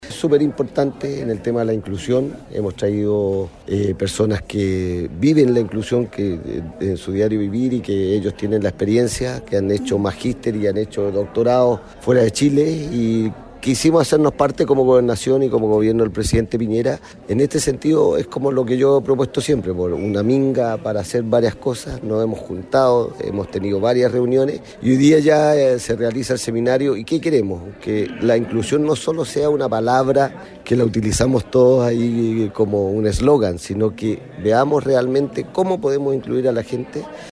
El Gobernador Fernando Bórquez, remarcó que la inclusión no se debe ver sólo como un slogan, sino que es necesario hacer participes a todas las personas.